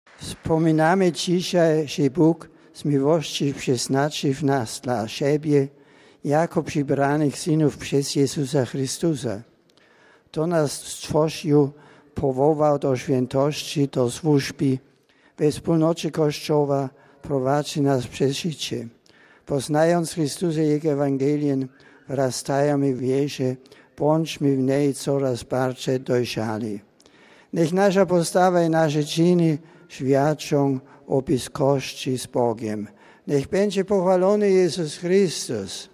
Do tematu dzisiejszej katechezy Papież nawiązał też w słowie do Polaków. Słuchaj papieskich pozdrowień po polsku: RealAudio